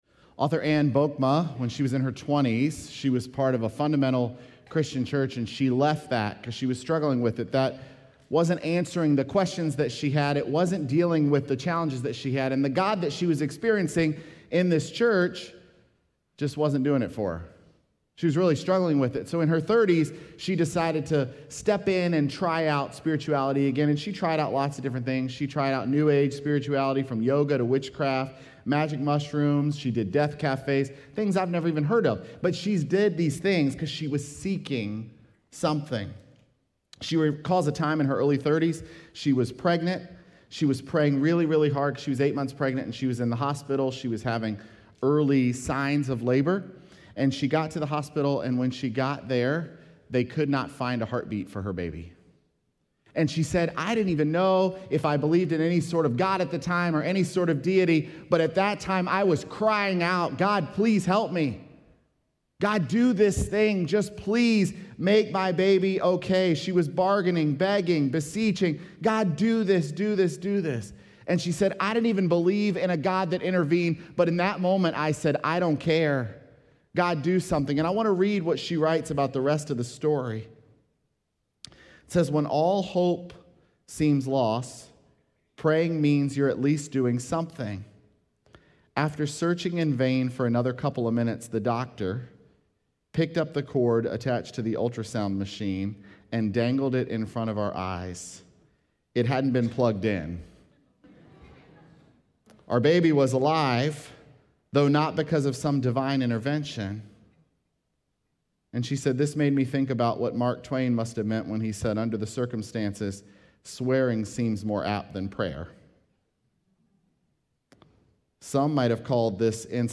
Sermons
Feb11SermonPodcast.mp3